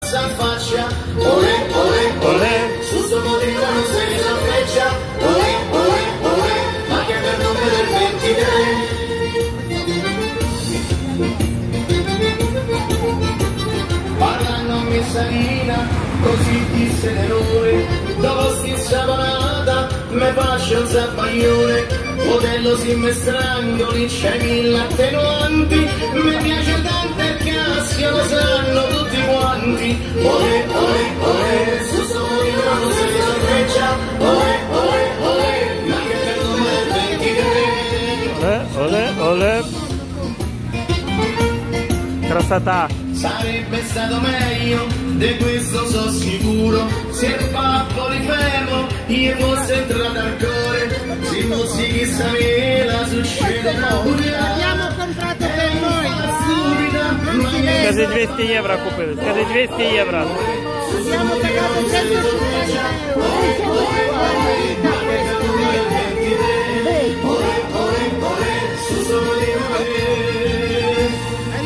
Песня называется Stornelli zozzoni, это такие народные частушки, не совсем приличные, а исполнители, судя по всему, уличные музыканты.
я не знаю язык-очень понравилась заводная мелодия
Эта запись сделана просто на улице, среди туристов. Пели какие-то безымянные уличные музыканты, непрофессионалы.